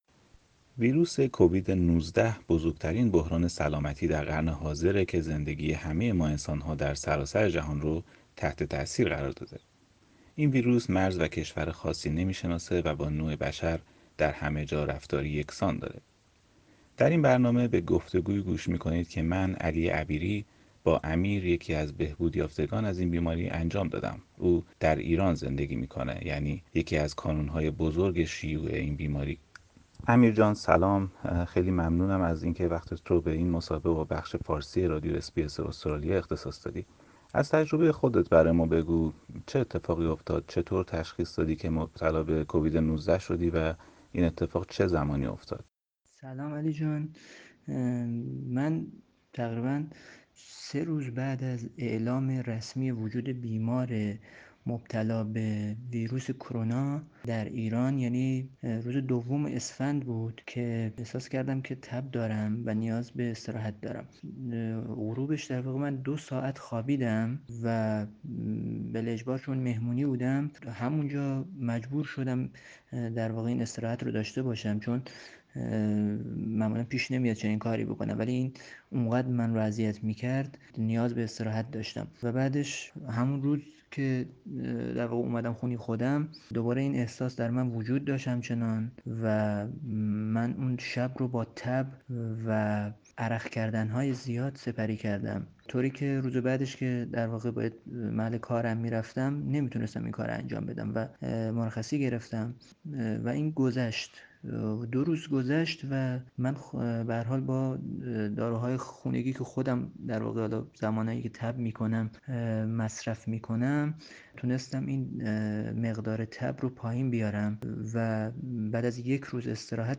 در این برنامه، به گفتگویی گوش می کنید با یکی از بهبودیافتگان از این بیماری.
interview_1.mp3